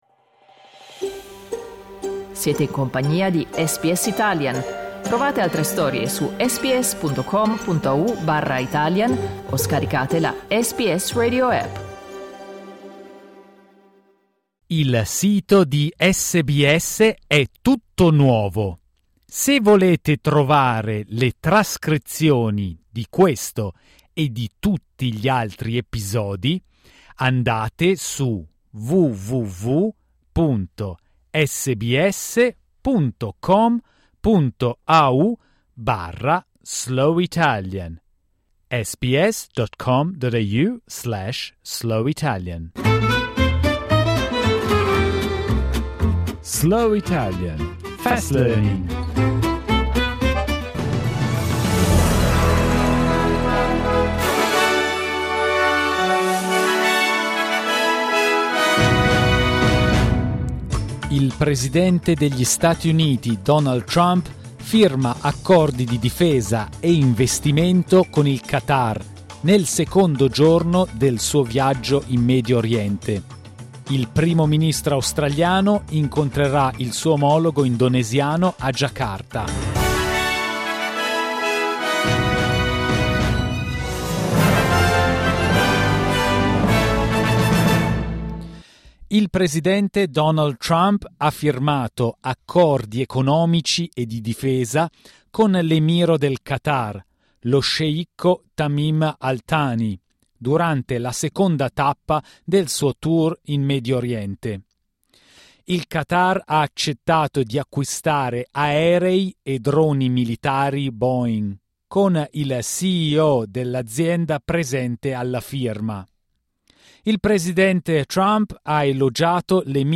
SBS Italian News bulletin, read slowly.